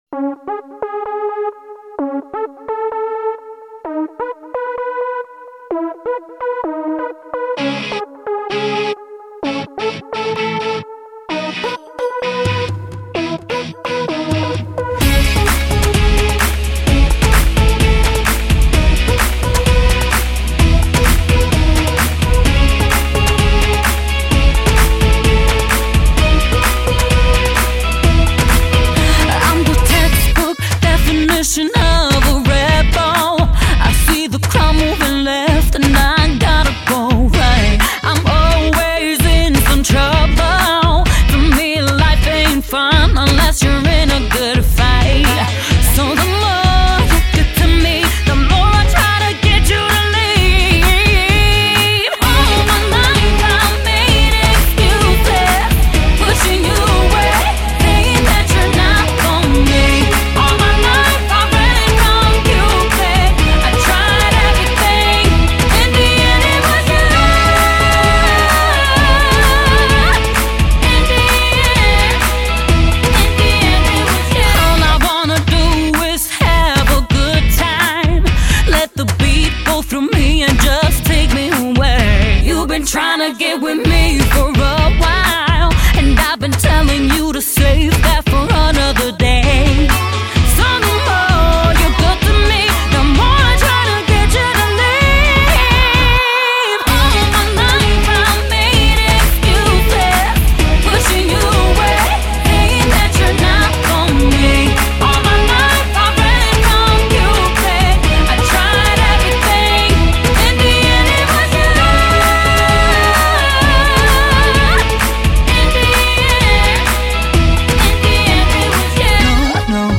Жанр:Pop